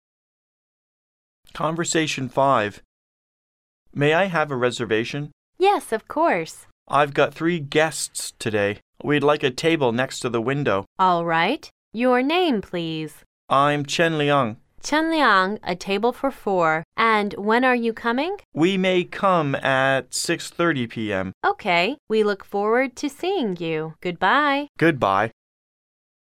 Conversation 5